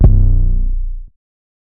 808 (YOSEMITE B).wav